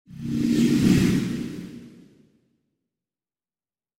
Звуки тумана
Кто-то или что-то промелькнуло в тумане